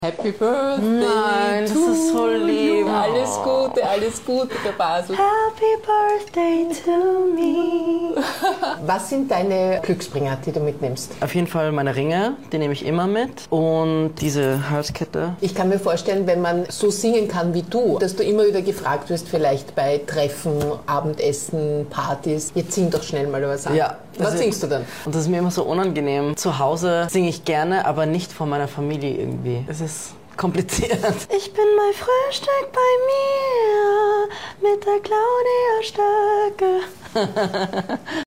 JJ interview for HitRadio Ö3. sound effects free download